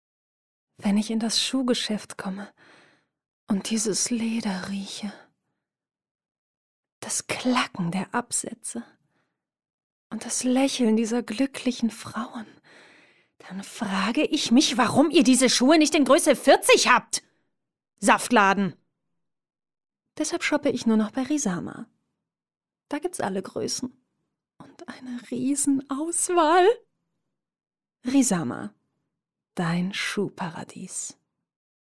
Comedy Monolog